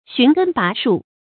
寻根拔树 xún gēn bá shù
寻根拔树发音
成语注音ㄒㄩㄣˊ ㄍㄣ ㄅㄚˊ ㄕㄨˋ